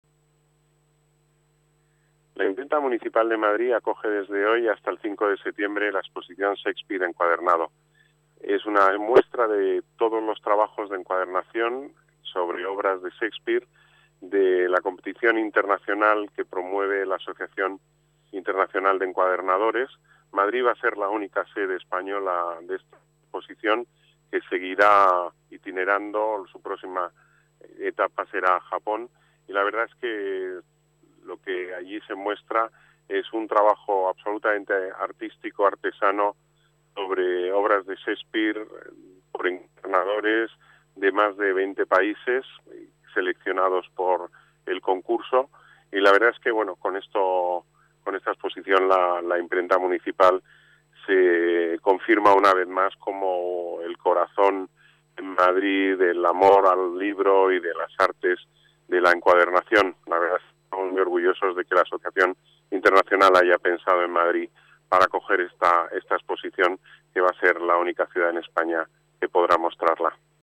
Nueva ventana:Declaraciones del delegado de Las Artes, Deportes y Turismo, Pedro Corral: inauguración exposición Shakespeare